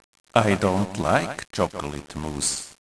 moose.wav